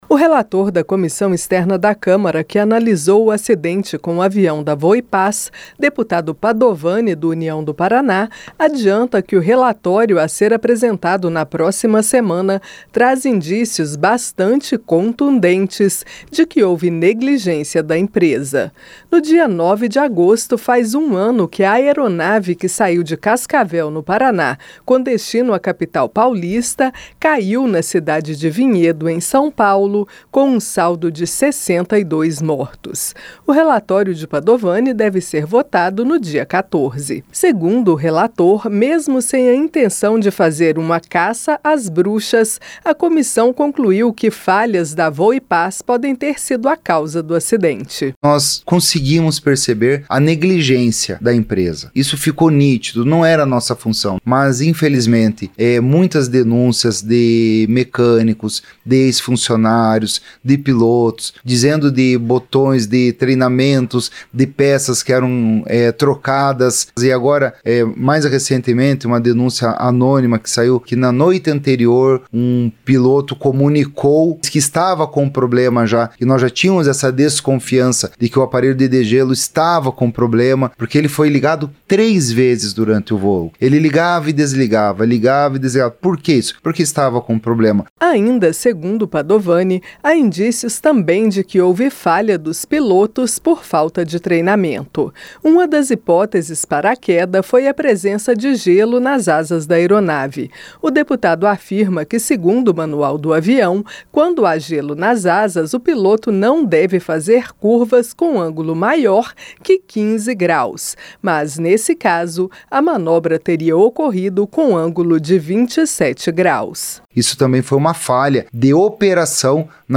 Em entrevista à Rádio Câmara, ele afirmou que, embora a comissão não tivesse como função investigar o caso, ficou nítida a negligência da Voepass.